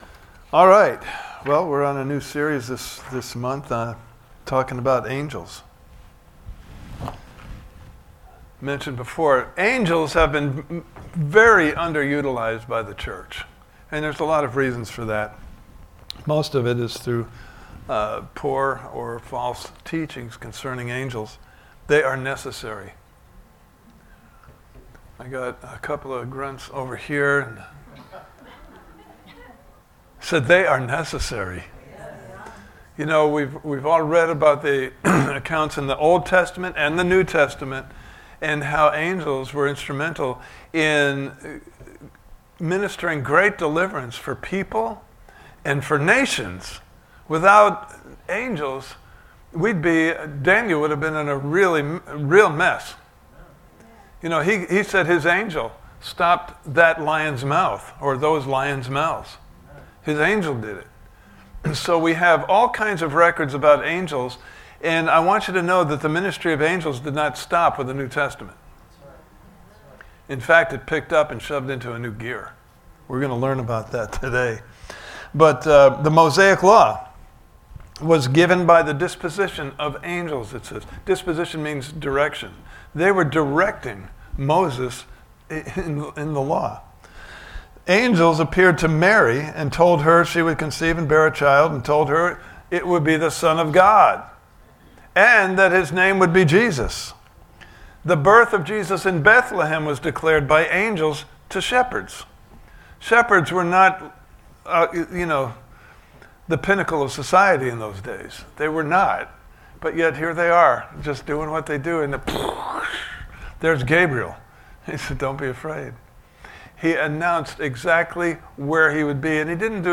Series: The Amazing Ministry of Angels! Service Type: Sunday Morning Service « Part 3 & 4: Ready to Act!